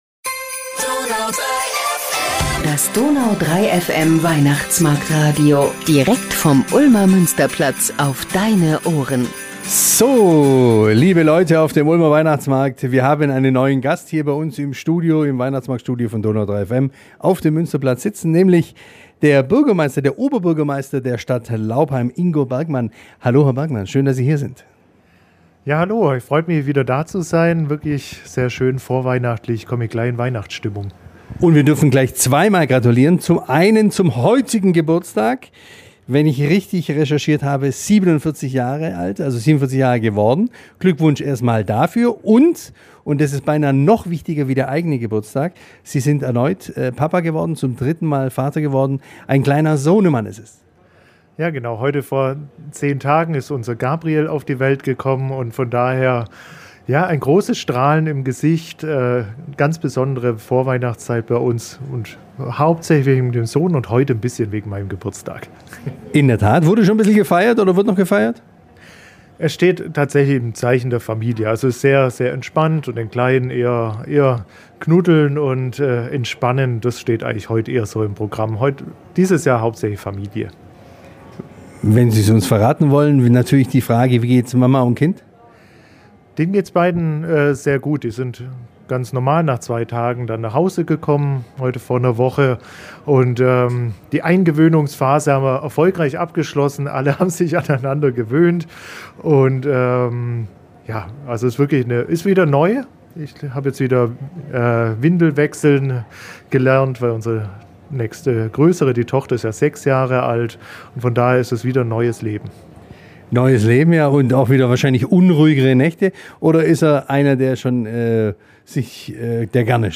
Das DONAU 3 FM Weihnachtsmarkt- Radio mit Ingo Bergmann Oberbürgermeister von Laupheim